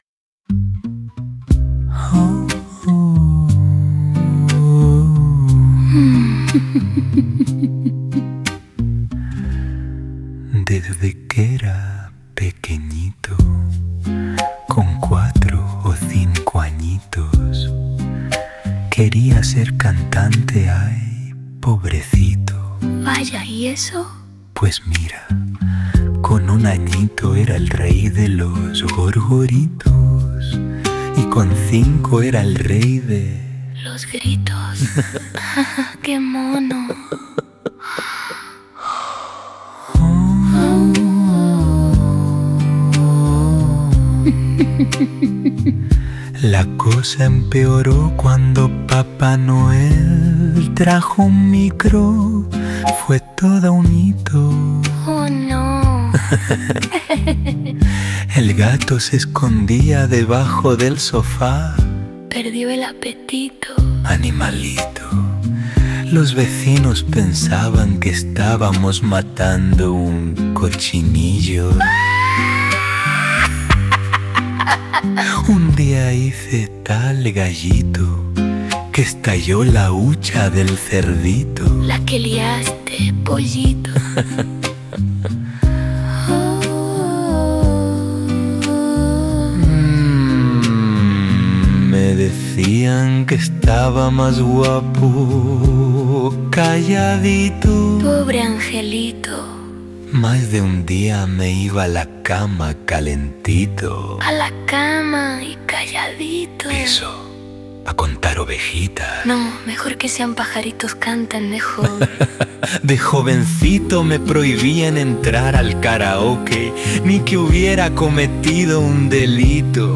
Canciones de muestra con voces clonadas
Son cinco canciones en las que cantamos tres personas de las que conocéis sobradamente nuestras voces